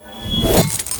bsword2.ogg